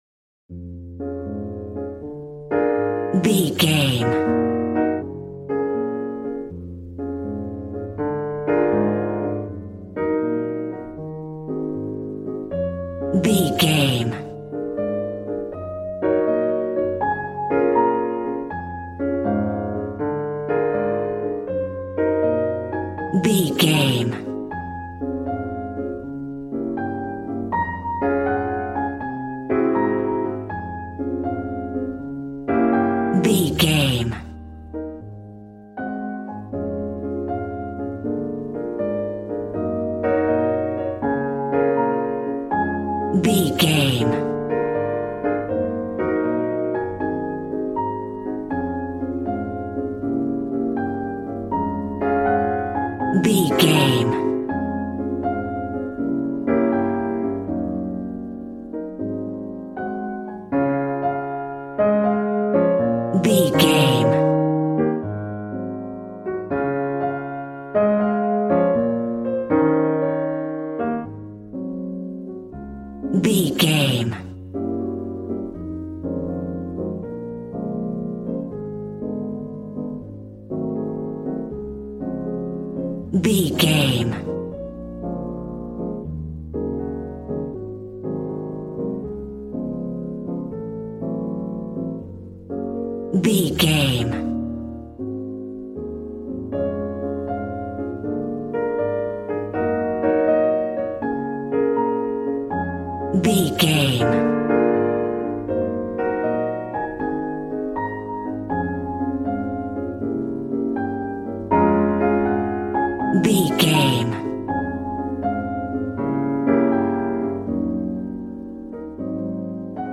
Smooth jazz piano mixed with jazz bass and cool jazz drums.,
Aeolian/Minor
B♭
piano
drums